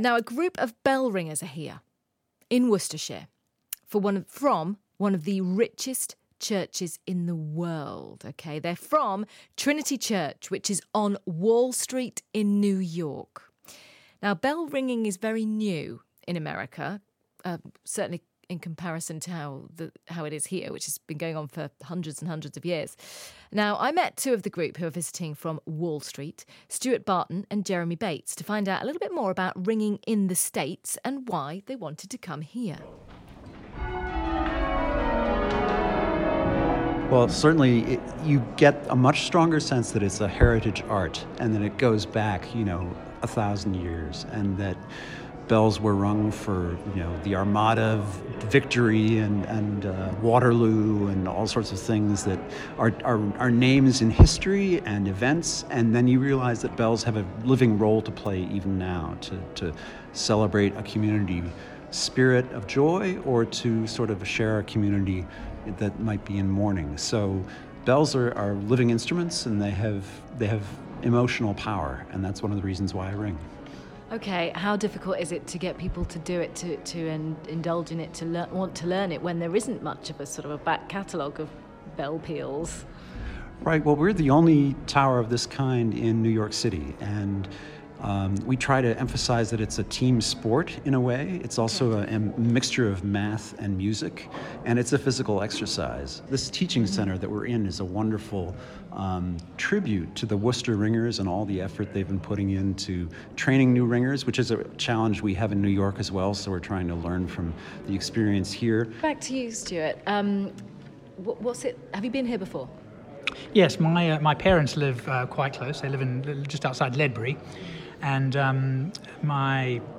An interview with ringers from Trinity Church, Wall Street, New York